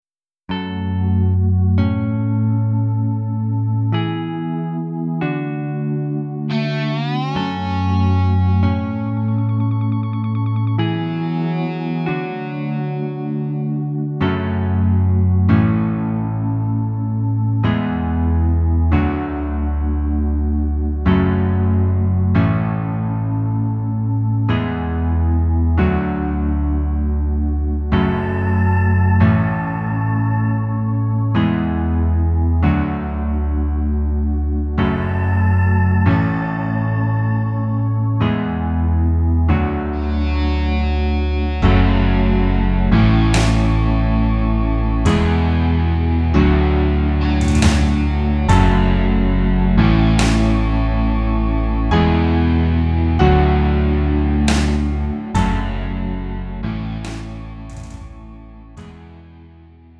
음정 -1키 3:17
장르 가요 구분 Lite MR